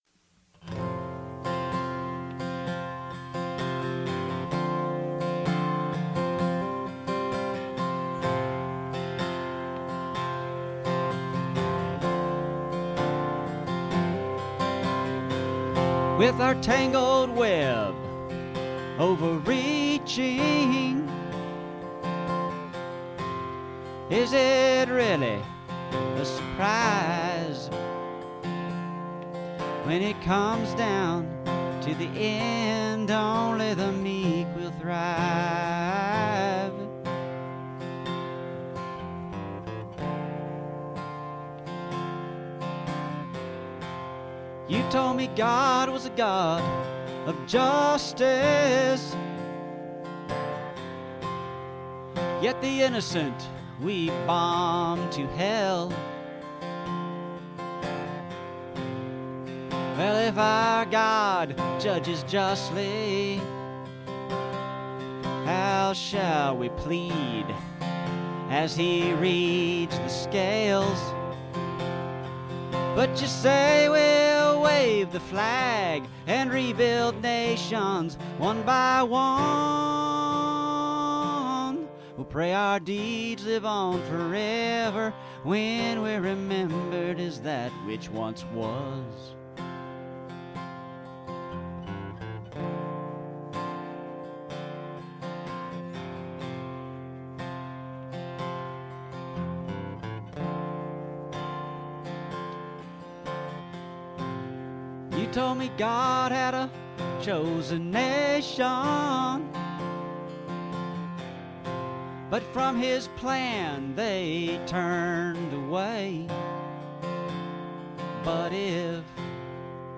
Tune guitar down one whole step!